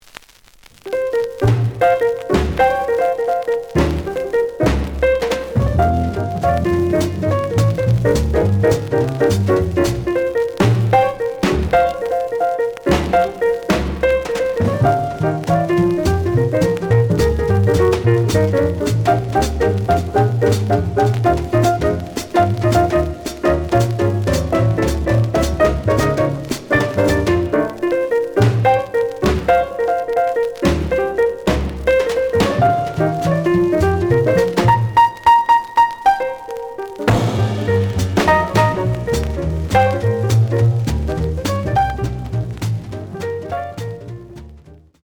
The audio sample is recorded from the actual item.
●Genre: Jazz Funk / Soul Jazz
Some noise on both sides.